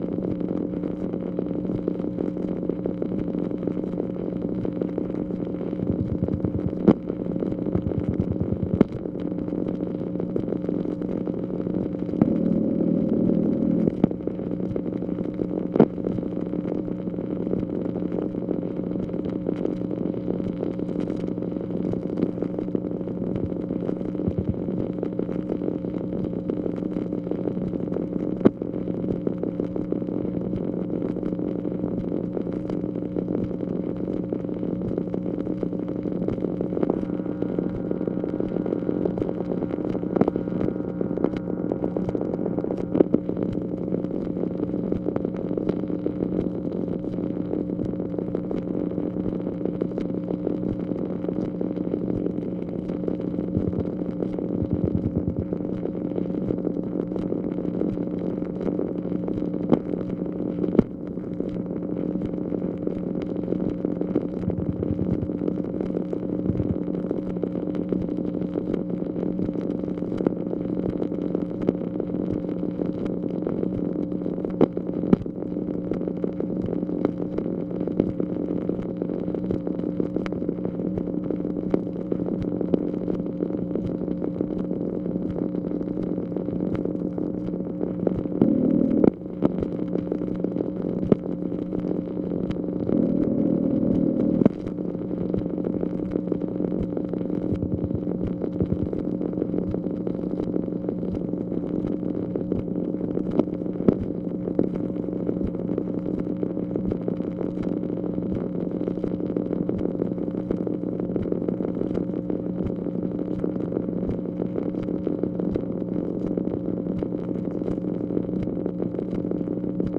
MACHINE NOISE, February 12, 1965